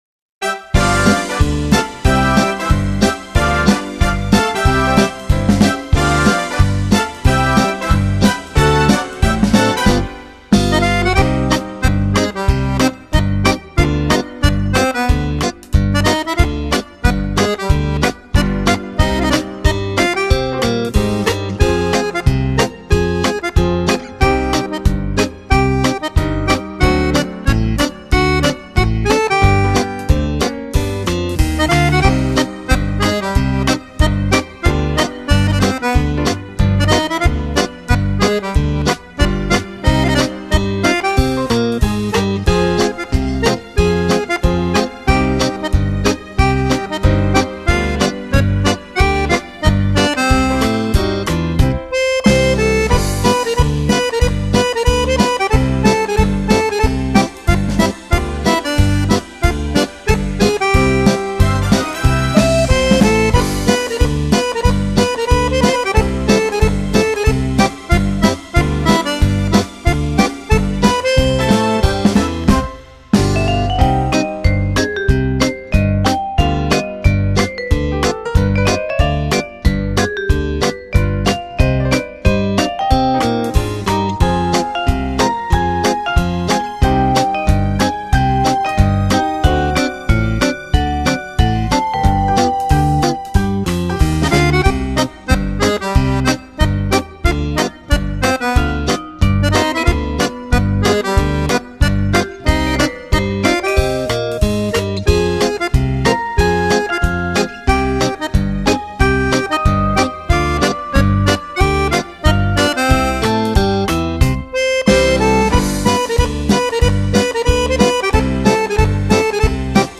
Genere: Fox